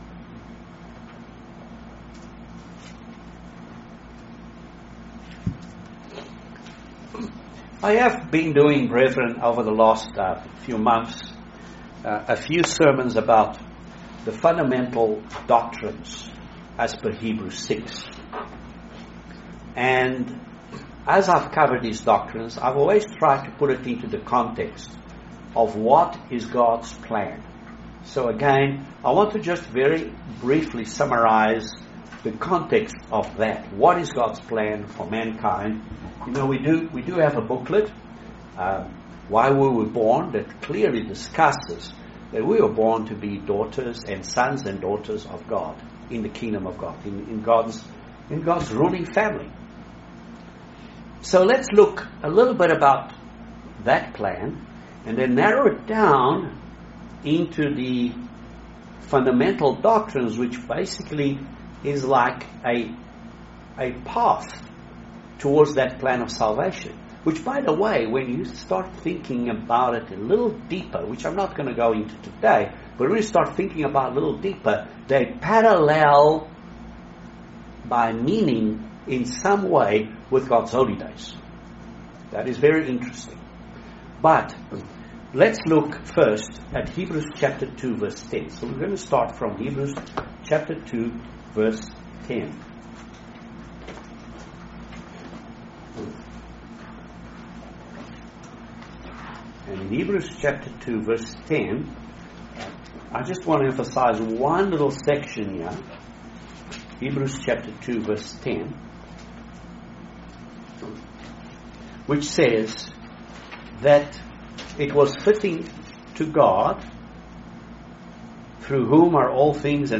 Join us for this excellent video sermon about the subject of Baptism and the laying on of hands by one of God's true ministers. Learn what God expects us to do in this way, and how to obey God's commands.